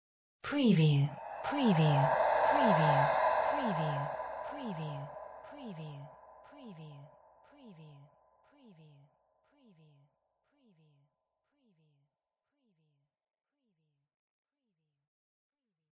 Surreal Whisper FX 025
SCIFI_WHISPERS_SPOOKY_WBSD025
Stereo sound effect - Wav.16 bit/44.1 KHz and Mp3 128 Kbps
previewSCIFI_WHISPERS_SPOOKY_WBHD025.mp3